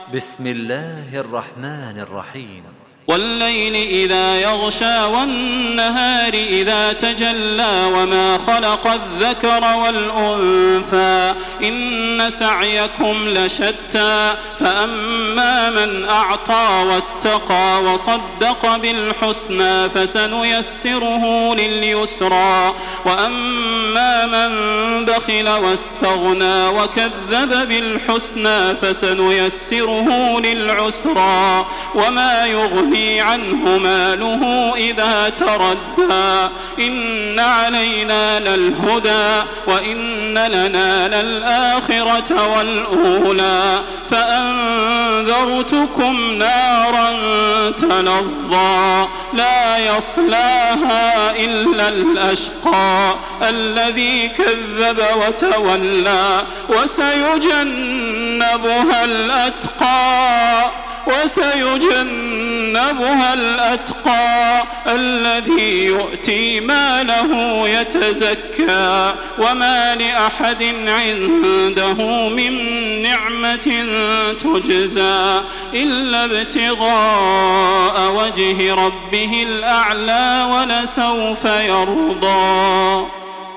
سورة الليل MP3 بصوت تراويح الحرم المكي 1427 برواية حفص عن عاصم، استمع وحمّل التلاوة كاملة بصيغة MP3 عبر روابط مباشرة وسريعة على الجوال، مع إمكانية التحميل بجودات متعددة.
تحميل سورة الليل بصوت تراويح الحرم المكي 1427